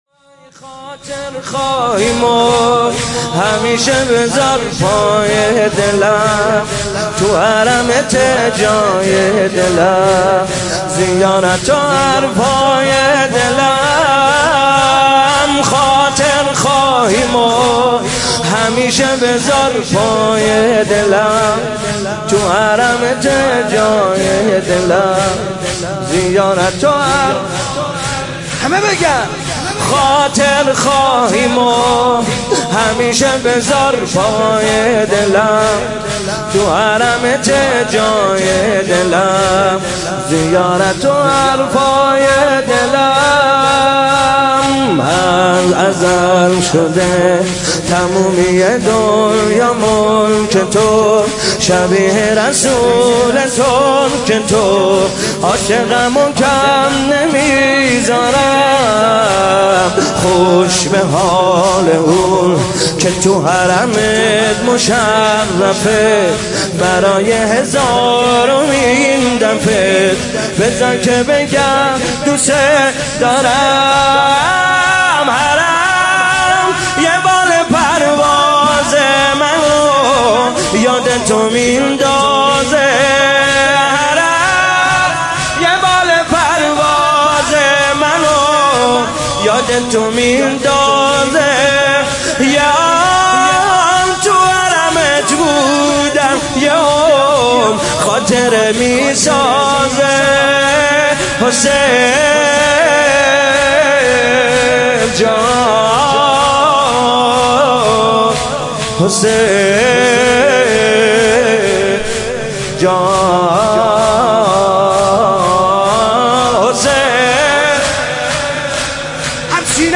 مداحی جدید
هیئت ثارالله مسجد الهادی (ع) تهران